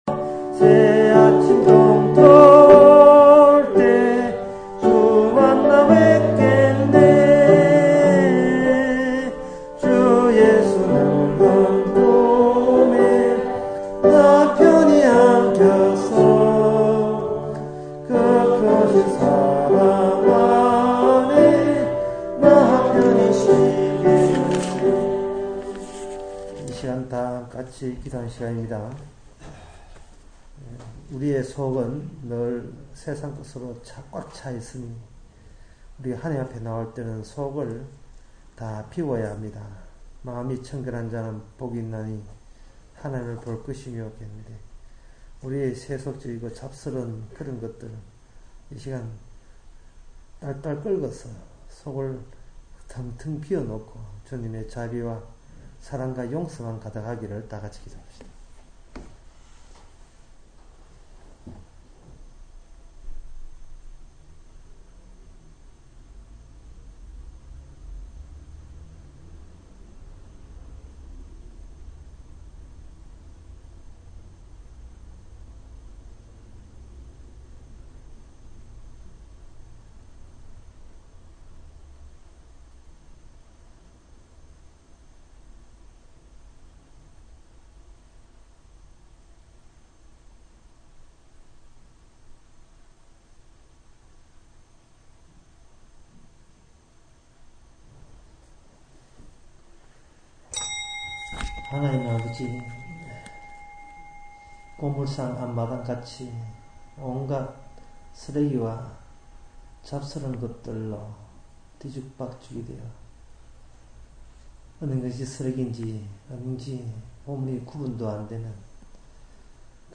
구약 설교, 강의